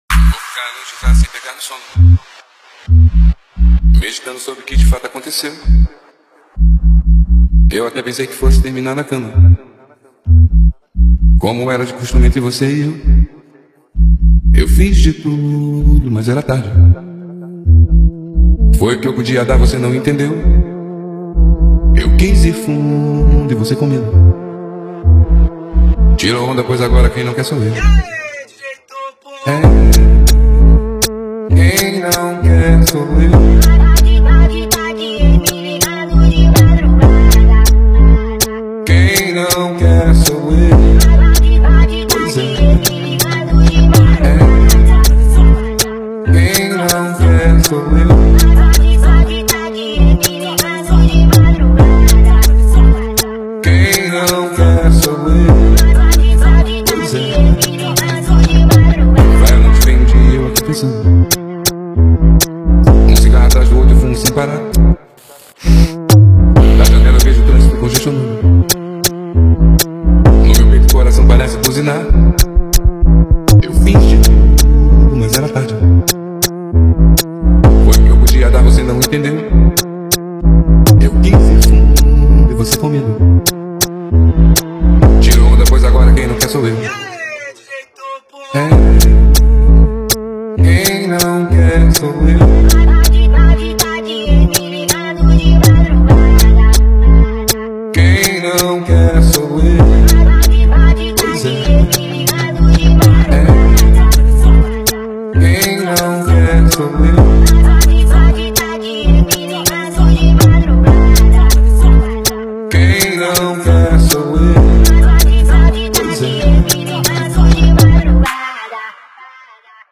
2025-01-11 18:16:58 Gênero: Trap Views